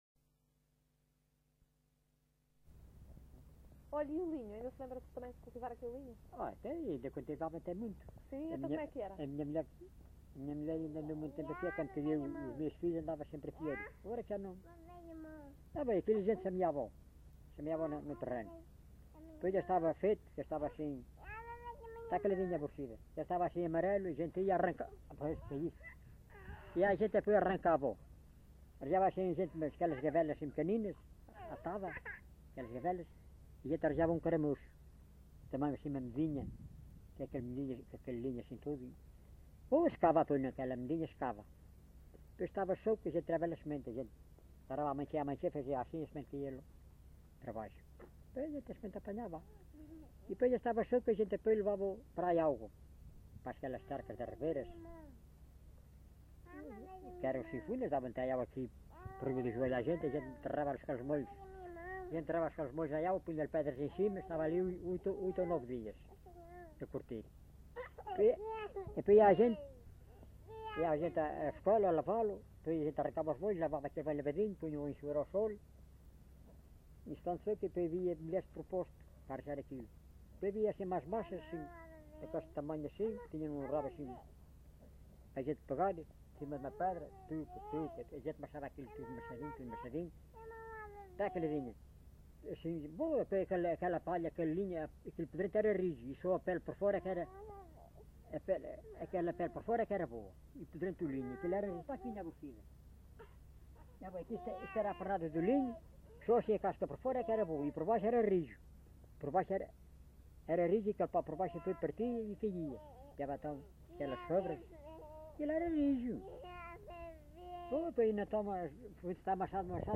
LocalidadeMonsanto (Idanha-a-Nova, Castelo Branco)